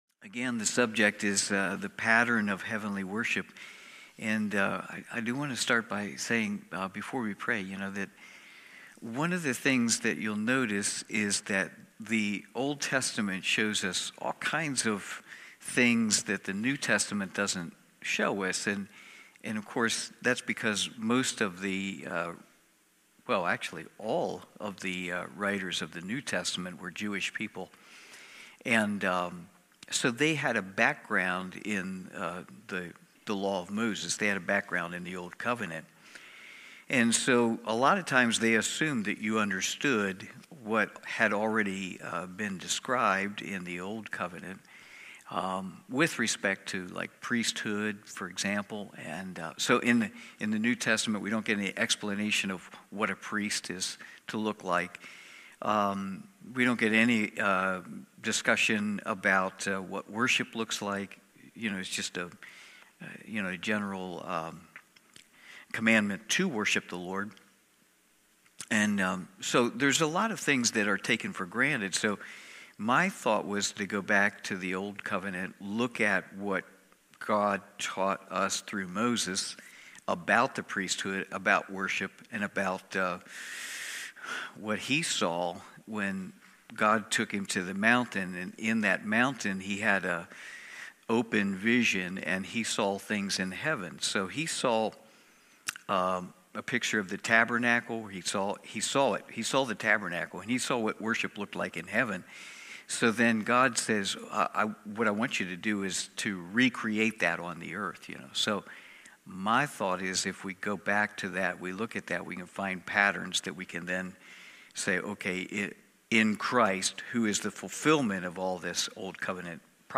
Cornerstone Fellowship Wednesday evening Bible study.